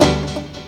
PIANOCHORD-L.wav